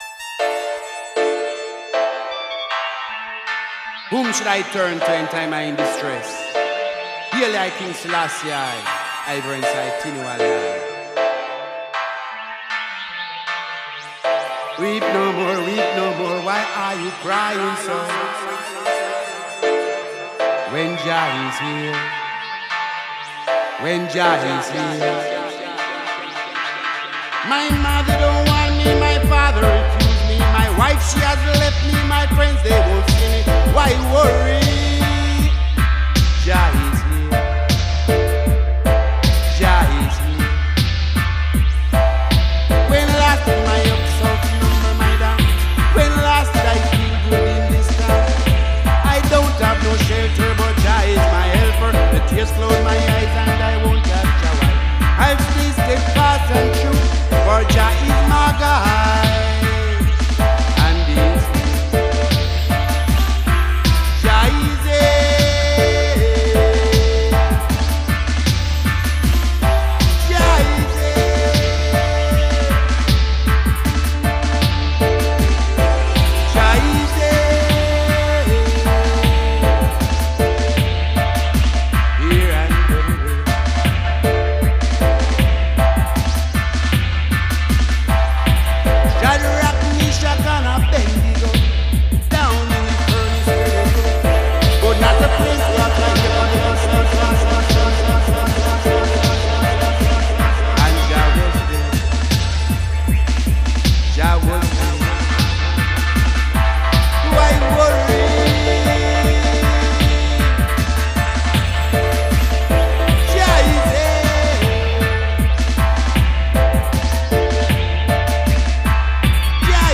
Voice Recorded at Conscious Sounds Studio London UK